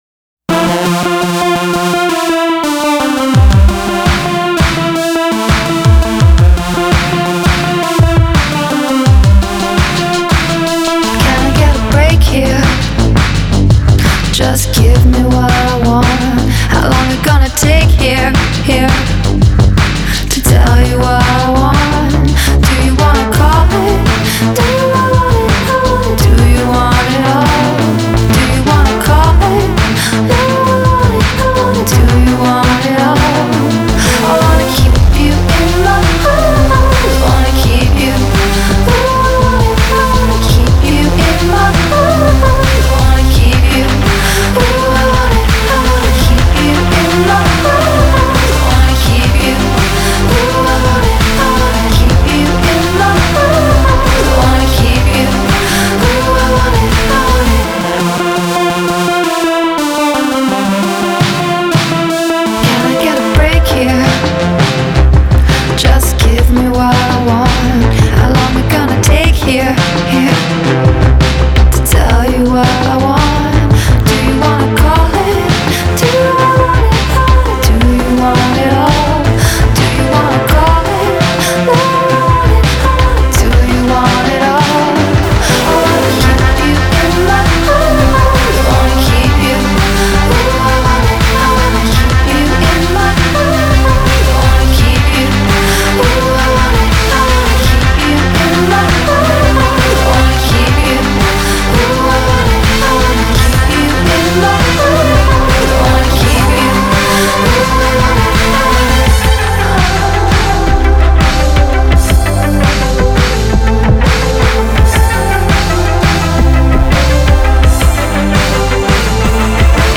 I’m also a sucker for minimalist electronic music.